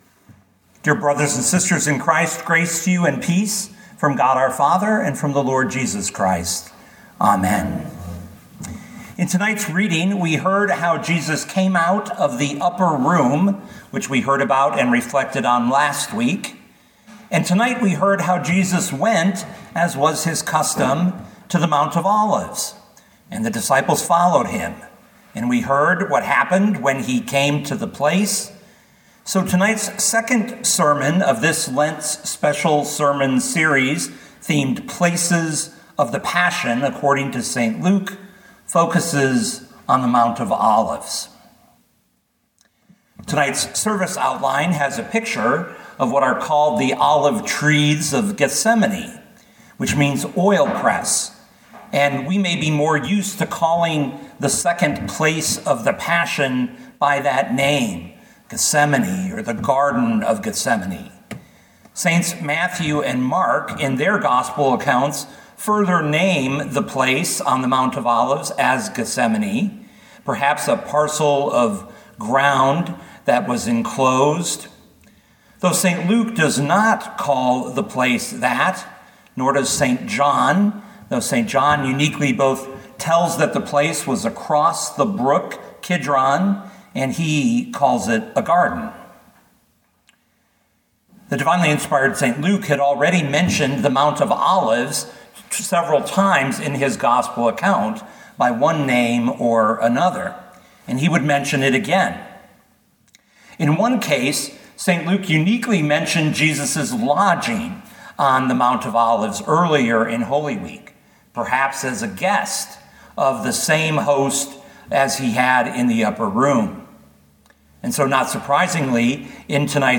2025 Luke 22:39-53 Listen to the sermon with the player below, or, download the audio.